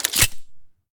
select-auto-shotgun-3.ogg